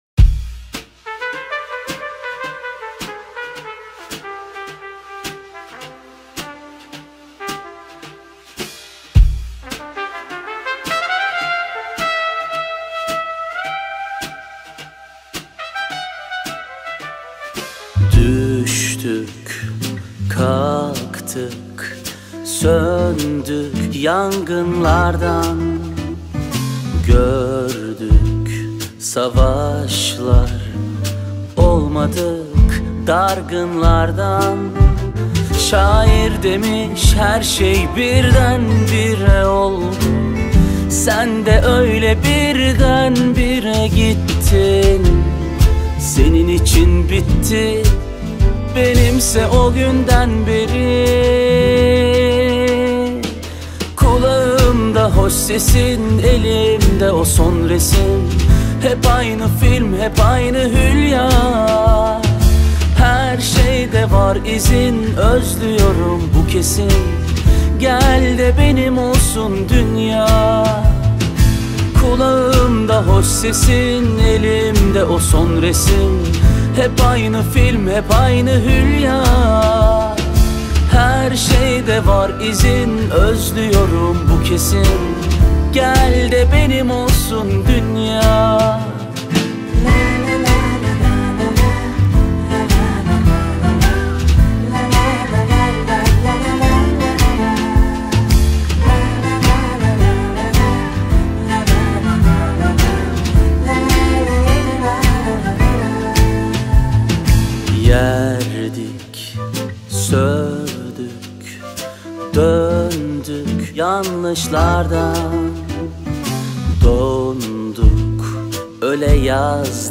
Жанр: Турецкие